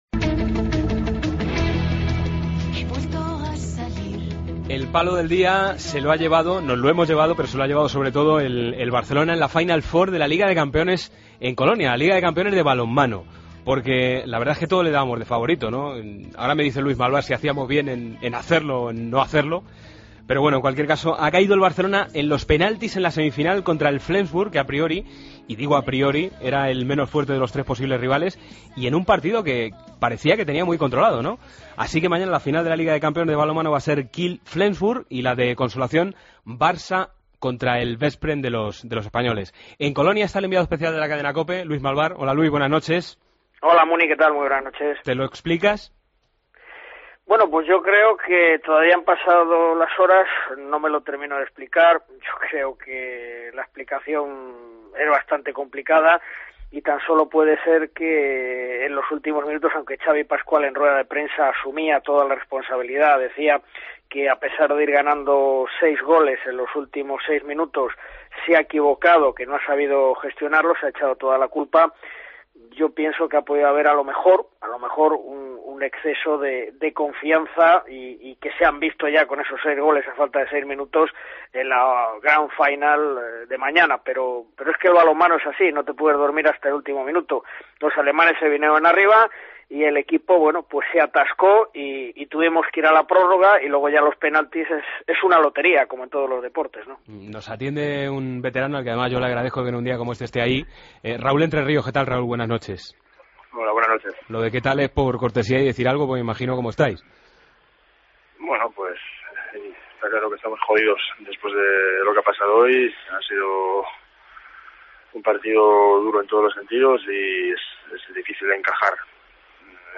Entrevista a Raúl Entrerríos: "Sentimos mucha rabial".